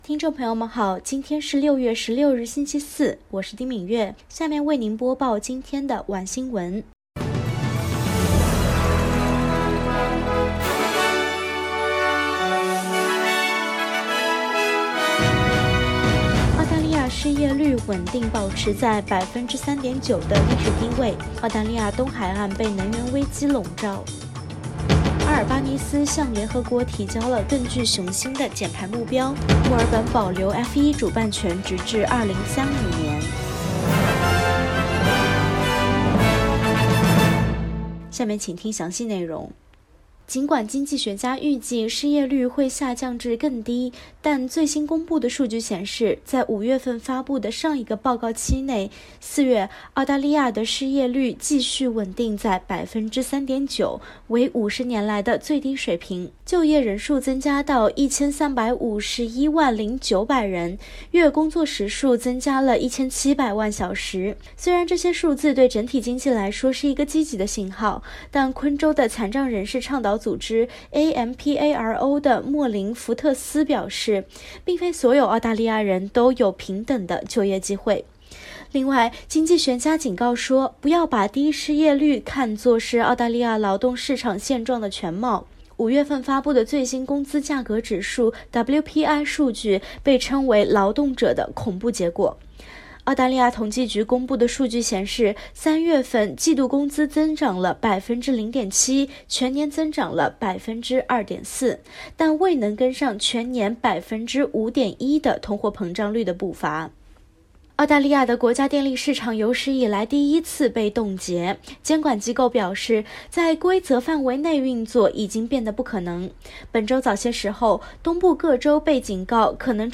SBS晚新闻（2022年6月16日）
SBS Mandarin evening news Source: Getty Images